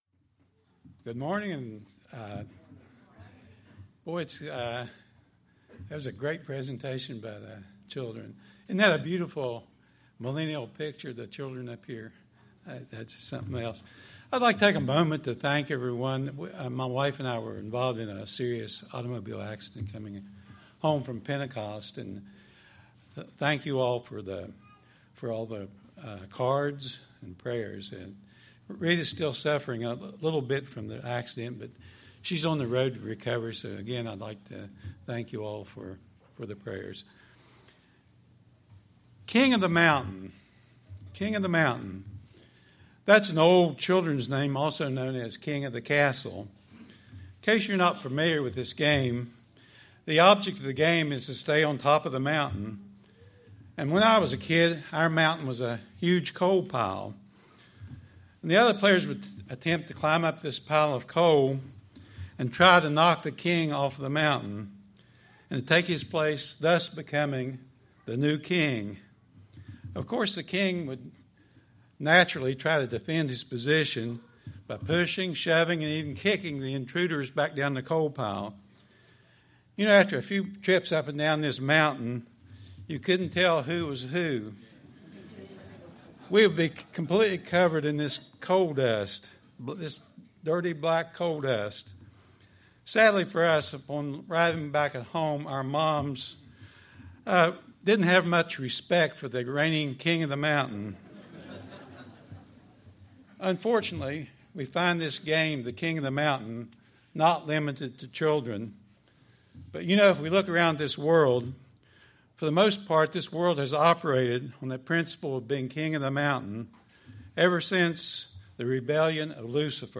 This sermon was given at the Ocean City, Maryland 2015 Feast site.